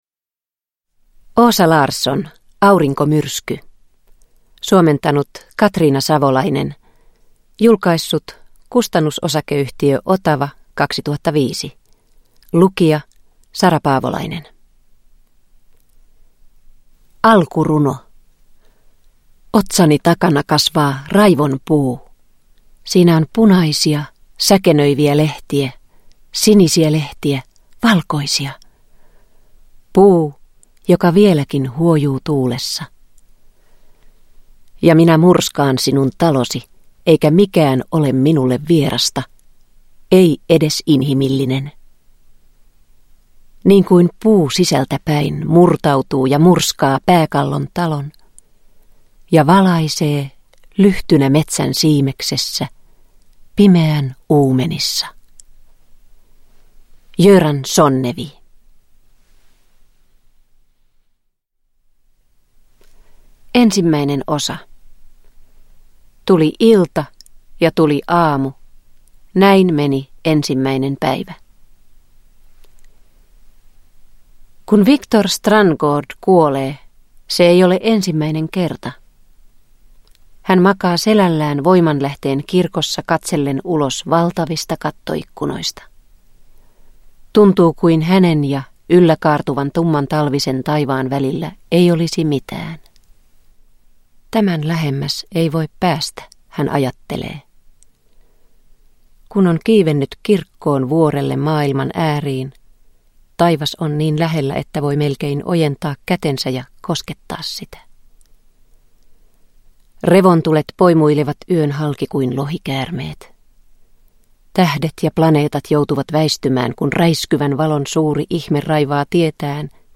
Aurinkomyrsky – Ljudbok – Laddas ner